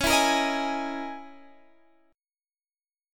Dbm6add9 chord